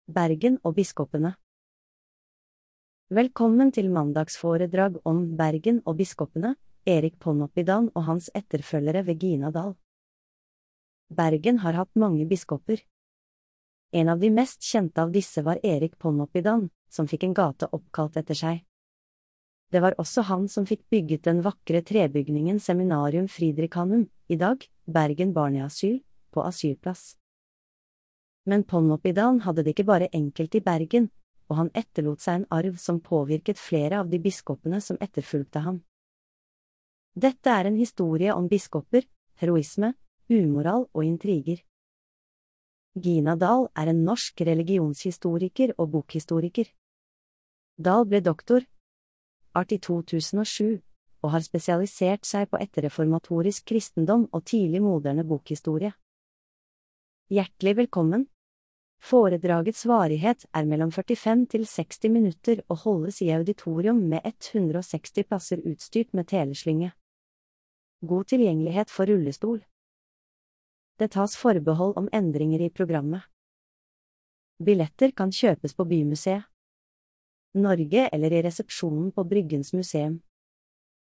Populærvitenskapelige foredrag med et vidt spenn av tema i auditoriet på Bryggens Museum.